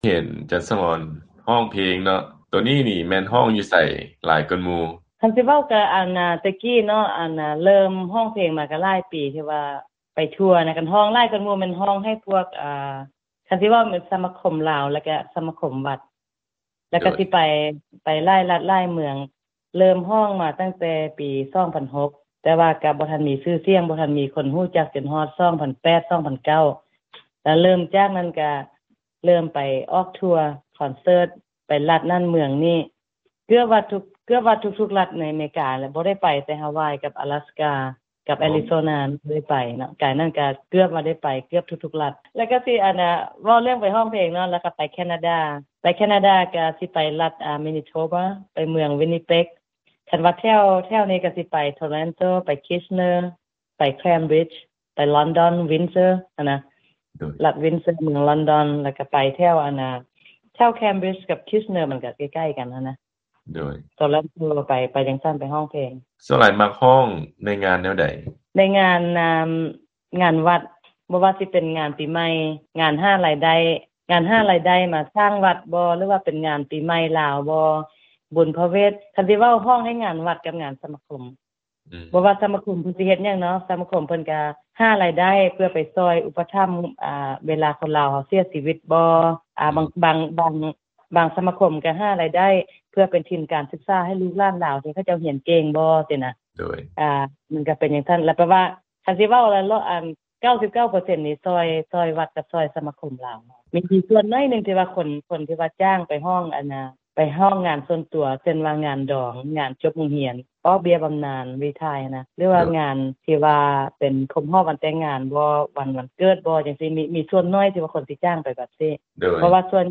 ການຮ້ອງເພງໃນງານຂອງຊຸມຊົນລາວ